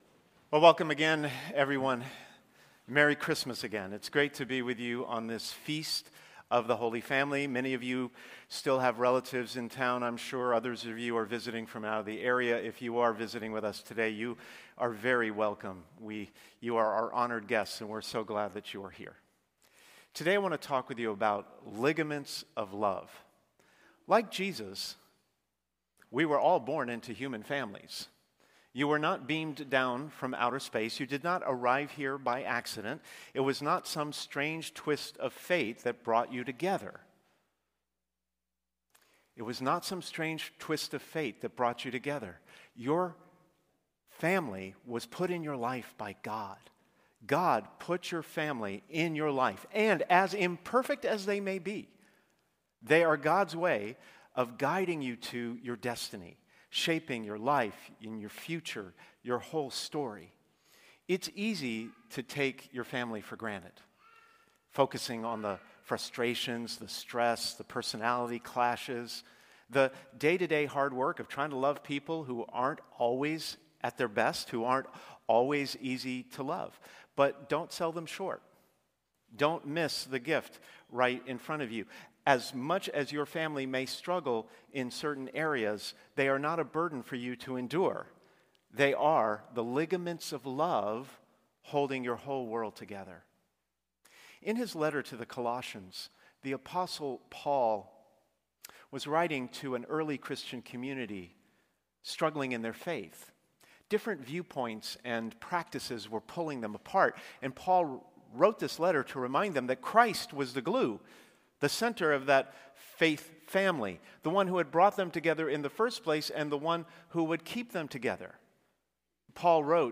On the Feast of the Holy Family, this homily explores how patience, forgiveness, and everyday acts of love are the quiet connective tissue that keep families strong, grounded in Christ, and moving toward their destiny. Strengthen those ligaments of love, and you’re not just shaping your family’s future—you’re securing your own.